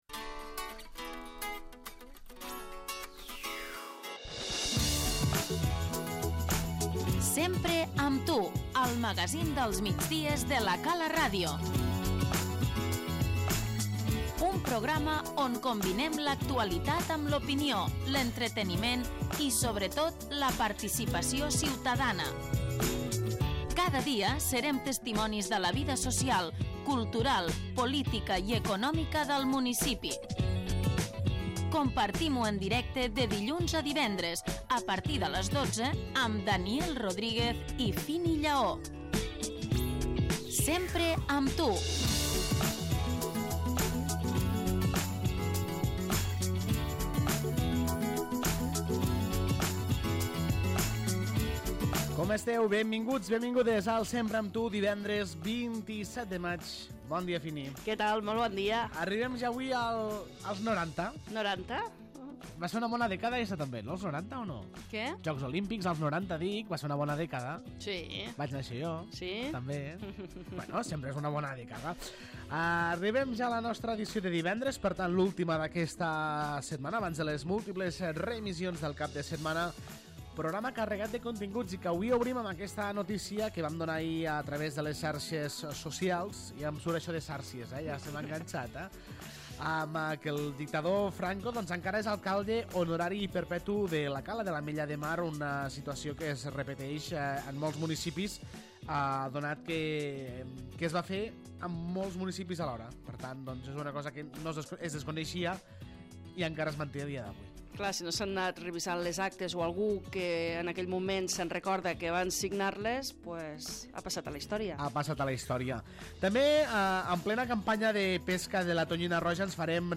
L'ENTREVISTA Puntualment ens acompanya l'alcalde de l'Ametlla de Mar, Jordi Gaseni, per repassar l'actualitat del municipi sense dubte marcada avui per aquest títol d'alcalde honorari i perpetu -pendent de revocar- a Franco.